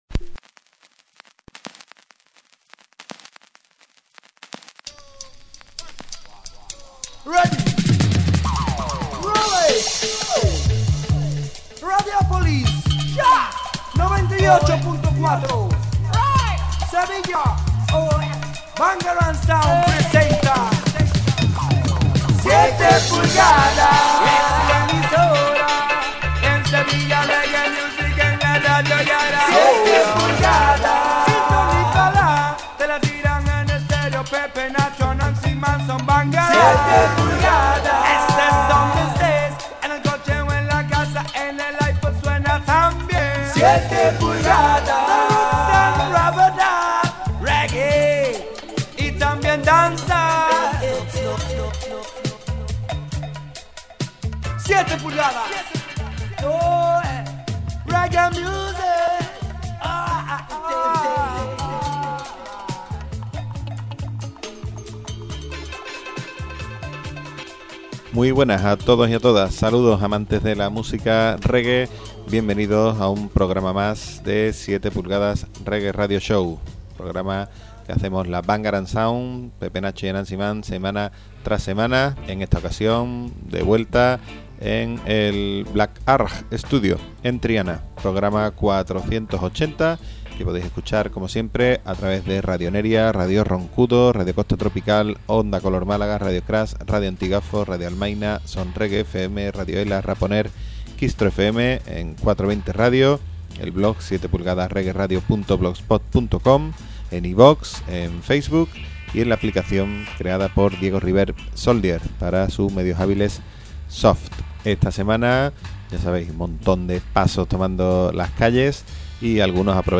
Rub-A-Dub
una selección en estricto vinilo
Mixtape